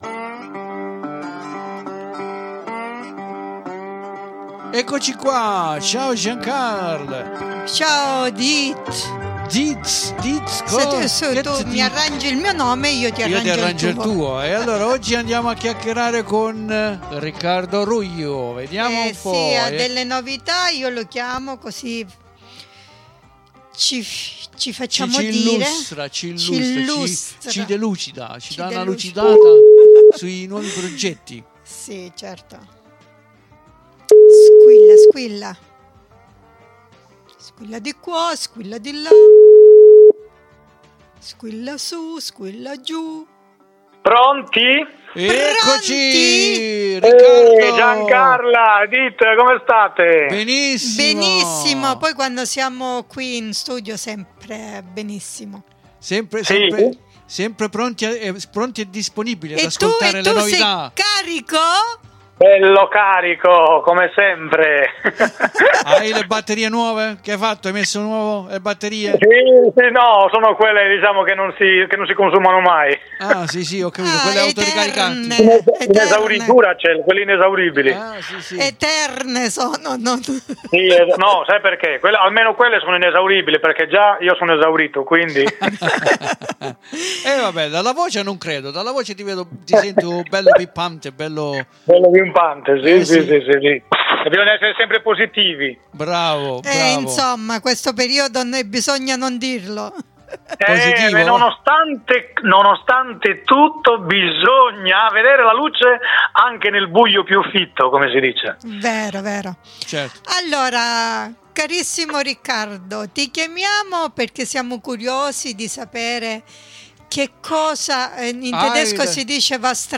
CHIARO CHE LE INTERVISTE VENGONO REGISTRATE E QUINDI A VOLTE CERTE NOTIZIE ARRIVANO TARDI MA DURANTE LA PUNTATA DEL 5 DI FEBBRAIO ABBIAMO DATO UN IN BOCCA AL LUPO A TUTTI COLORO CHE SONO IN QUEI CONTEST SANREMESI!